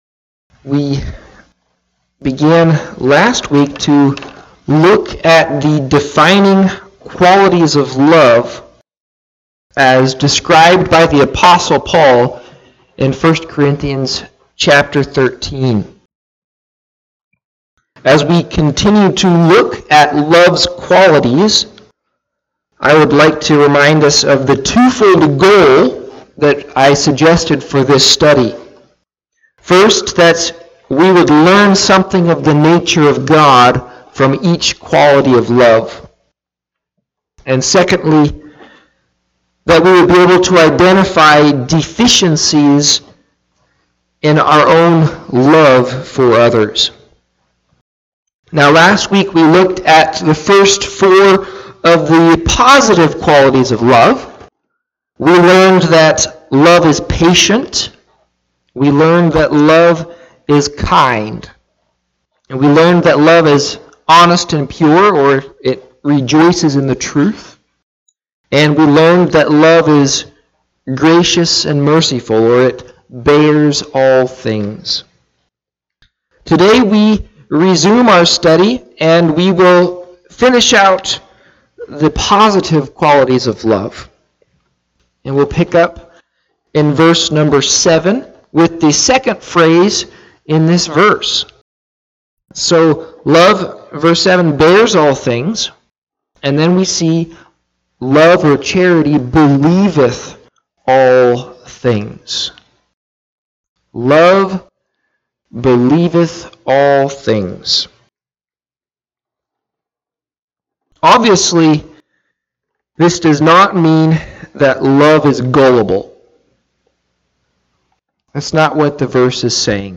Listen to Audio of the sermon or Click Facebook live link above.